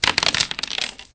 diceRoll.ogg